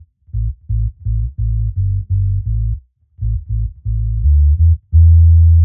Index of /musicradar/dub-designer-samples/85bpm/Bass
DD_JBass_85_A.wav